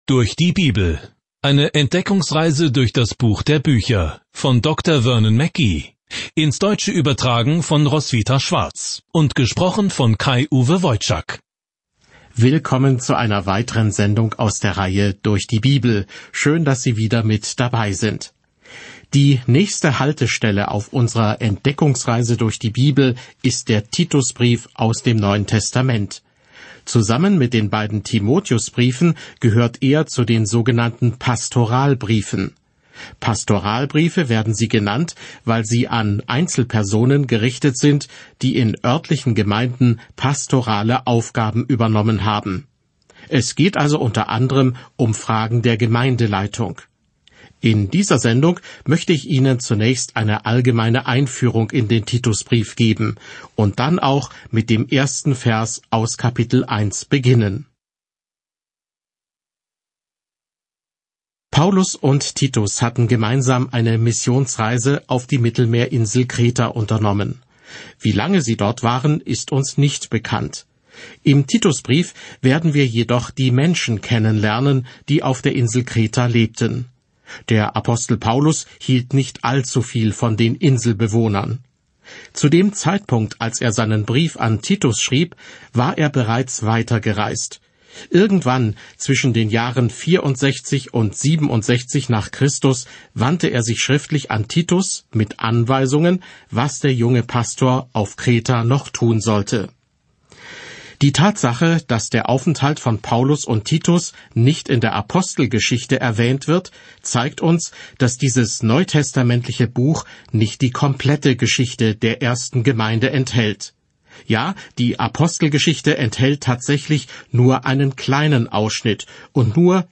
Die Heilige Schrift Titus 1:1 Diesen Leseplan beginnen Tag 2 Über diesen Leseplan Dieser Brief an einen jungen Pastor listet ein gegenkulturelles „Who-is-Who in der Kirche“ auf und beschreibt, wie alle Arten von Menschen einander dienen und lieben können. Reisen Sie täglich durch Titus, während Sie sich die Audiostudie anhören und ausgewählte Verse aus Gottes Wort lesen.